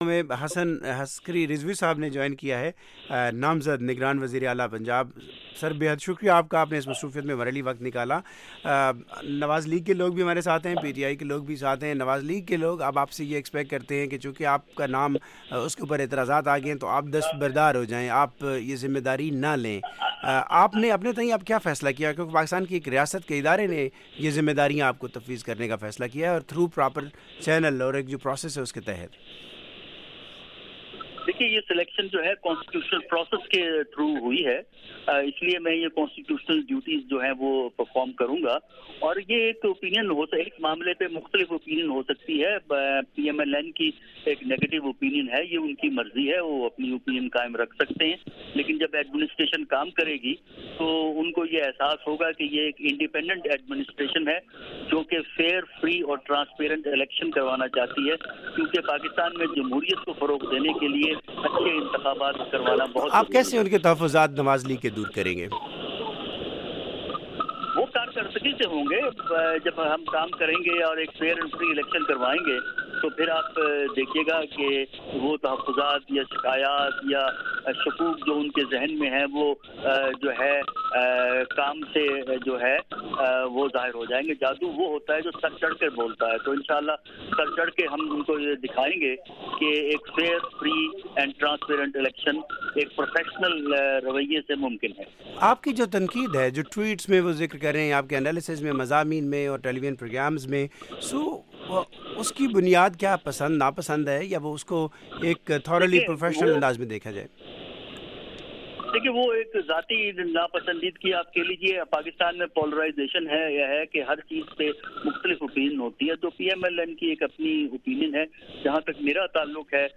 پنجاب کے نامزد وزیراعلیٰ حسن عسکری کا انٹرویو